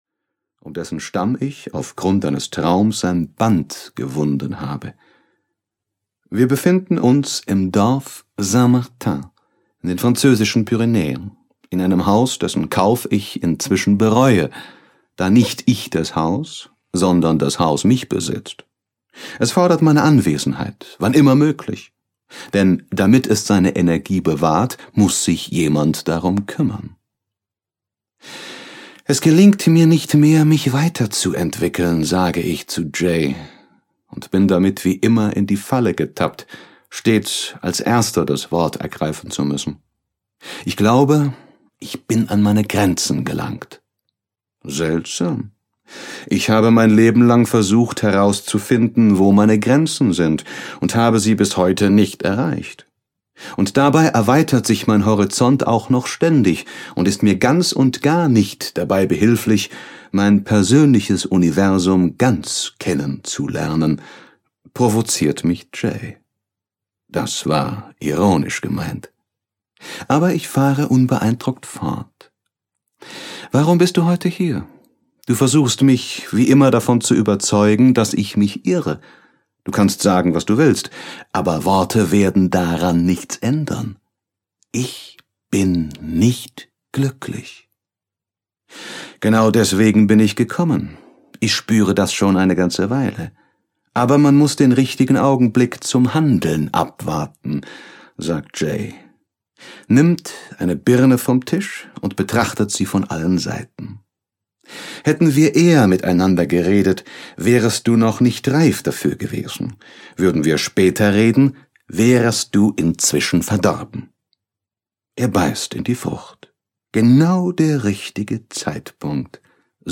Aleph - Paulo Coelho - Hörbuch